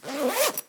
action_open_backpack_1.ogg